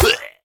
Minecraft Version Minecraft Version 25w18a Latest Release | Latest Snapshot 25w18a / assets / minecraft / sounds / mob / illusion_illager / death2.ogg Compare With Compare With Latest Release | Latest Snapshot
death2.ogg